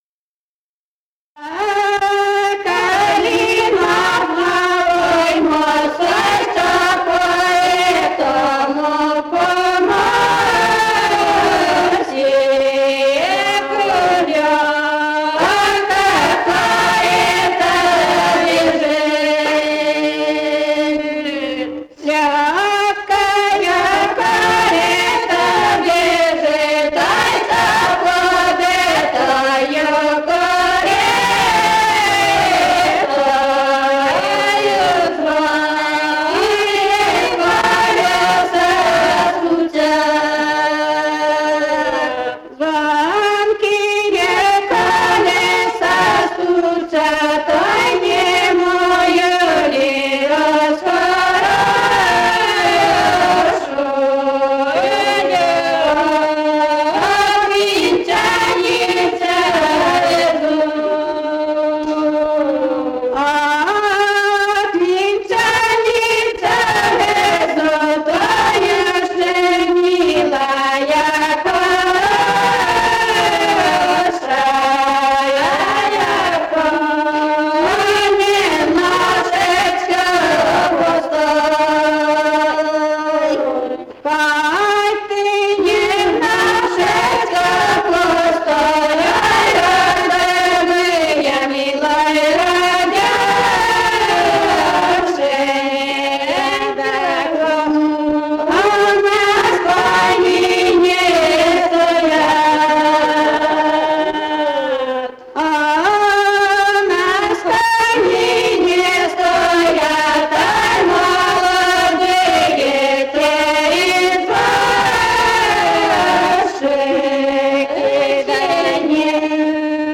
[Недозрелая калинушка] «За калинов новой мост» (лирическая).